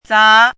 chinese-voice - 汉字语音库
za2.mp3